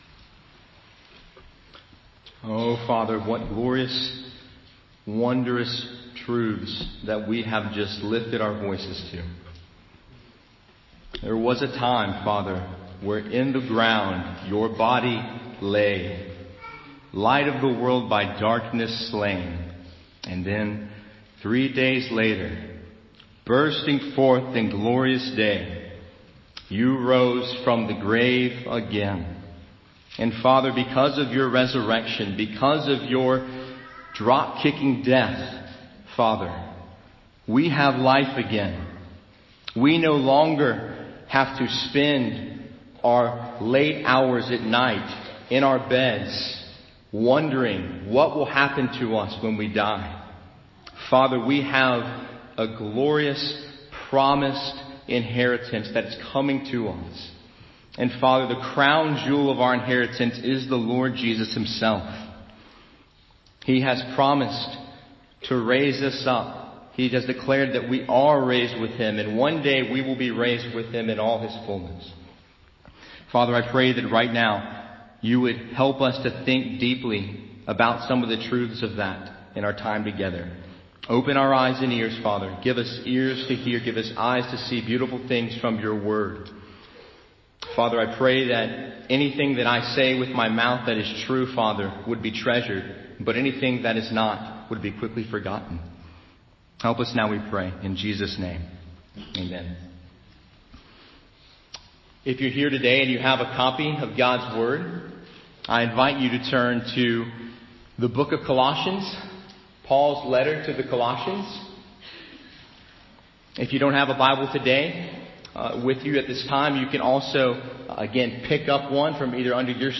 Sermon Audio 2018 April 1
Special message for Easter Sunday